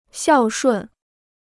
孝顺 (xiào shùn): 효도하는; 효성스러운.